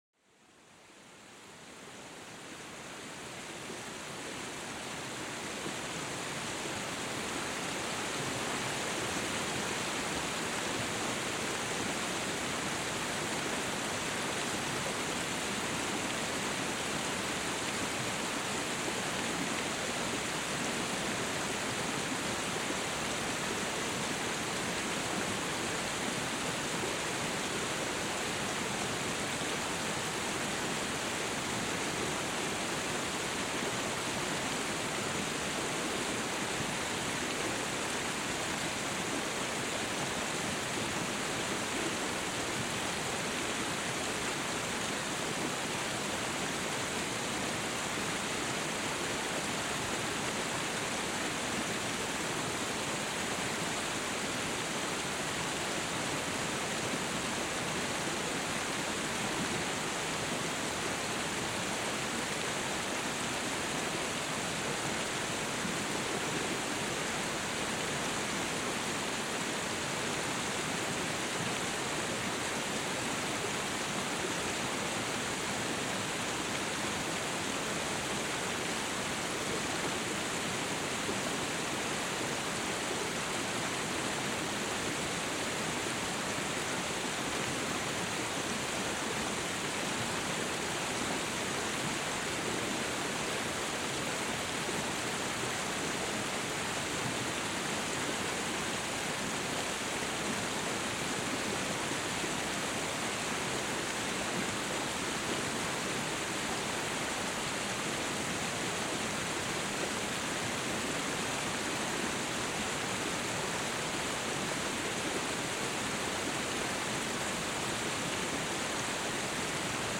Cascada relajante: una caída de agua para calmar la mente